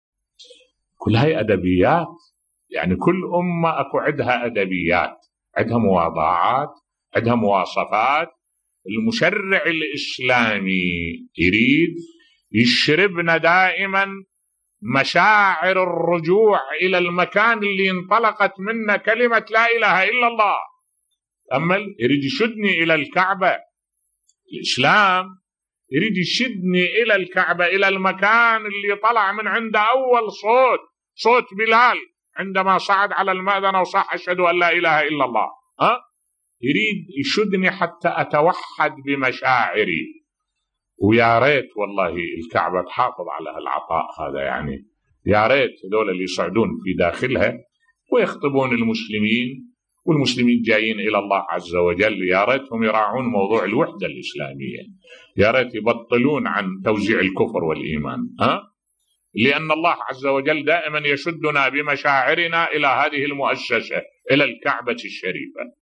ملف صوتی يدعو أئمة المسجد الحرام للحفاظ على الوحدة الاسلامية بصوت الشيخ الدكتور أحمد الوائلي